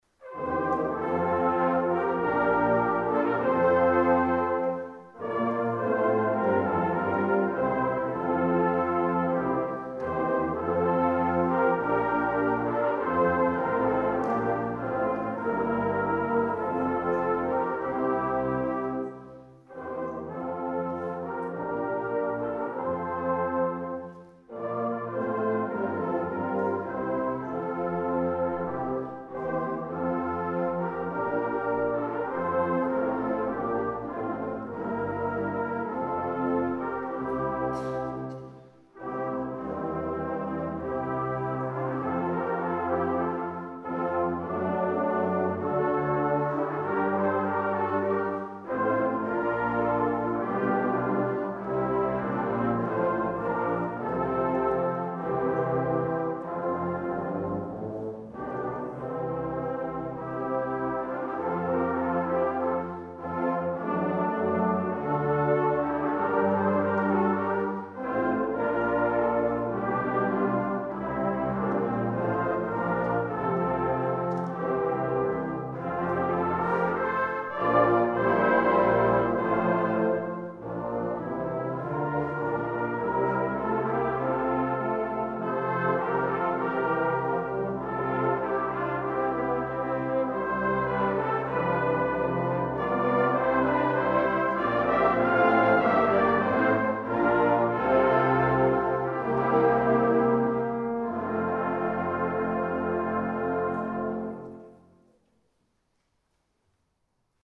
Bläsermusik proben
Die Aufnahmen wurden von verschiedenen Ensembles aufgenommen und zur Verfügung gestellt. Nicht mit dem Anspruch einer perfekten CD-Aufnahme, sondern als Hilfe für Chorleiterinnen und Chorleiter oder einzelne Mitspieler, um sich einen Klangeindruck der Stücke zu verschaffen.
Georg Philipp Telemann, Bläser des Bezirks Ortenau, Gloria S. 28